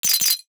NOTIFICATION_Glass_14_mono.wav